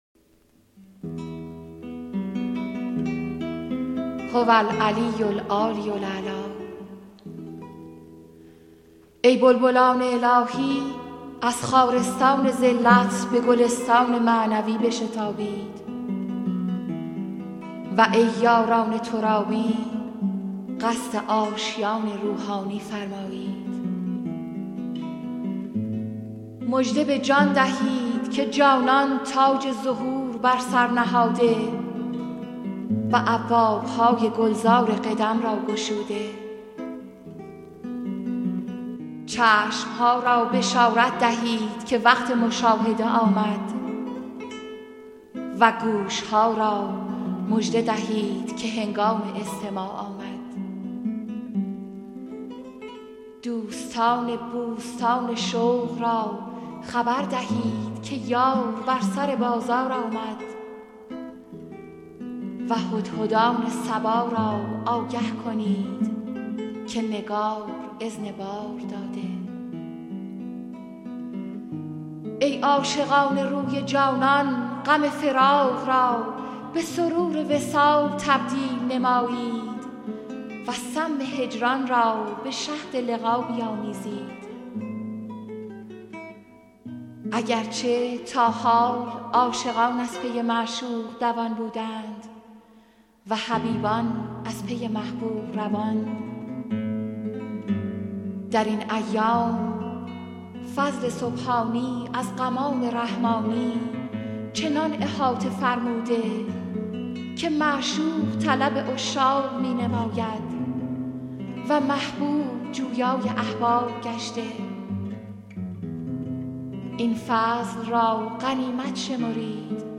سرود - شماره 2 | تعالیم و عقاید آئین بهائی